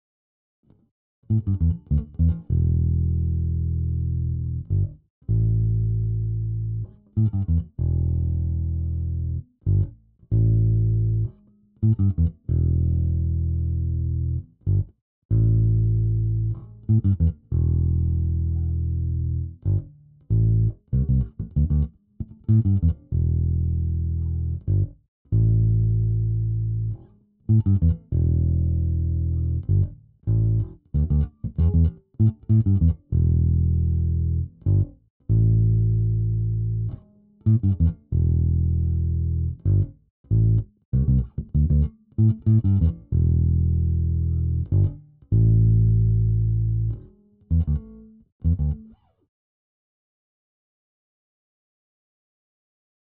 Nahráváno na Fender Precision American Vintage 2.
Nahrávky bez bicích:
Wet 1964 bez bicich